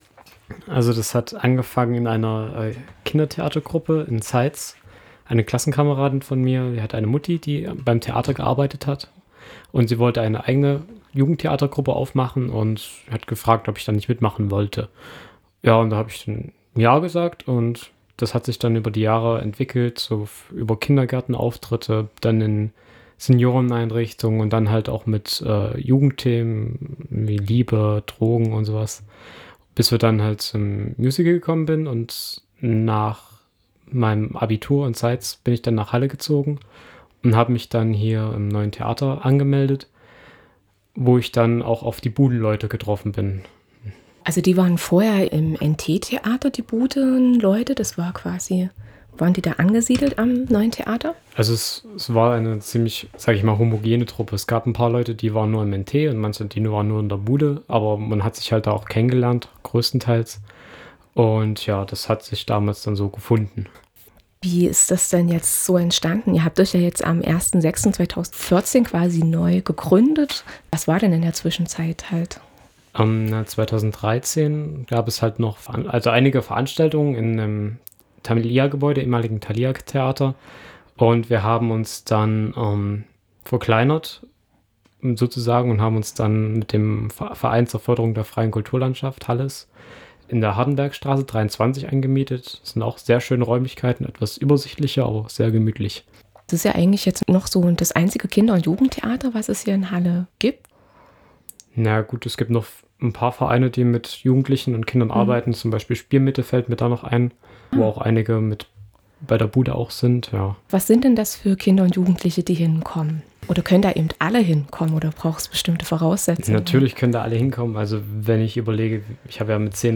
Er informiert im folgenden Audiobeitrag über den Neustart.